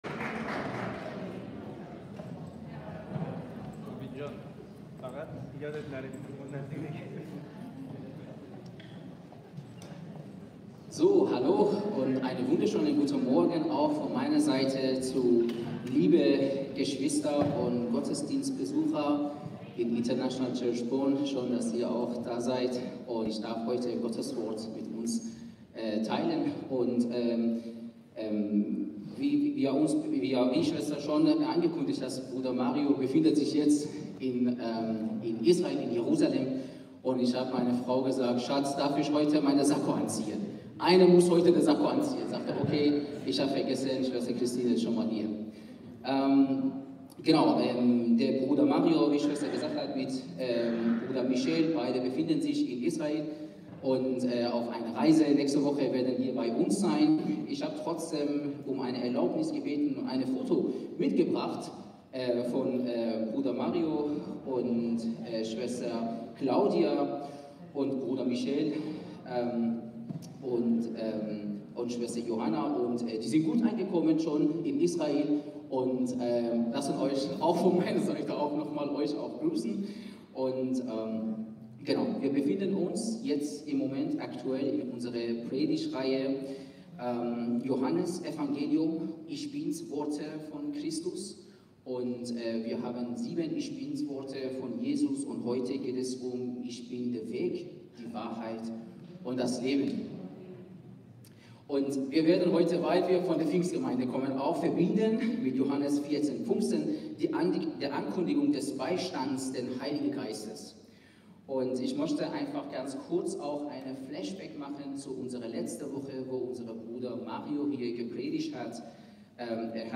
Wir befinden uns in unserer Predigtreihe „Ich-bin-Worte Jesu“.